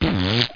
fart2.mp3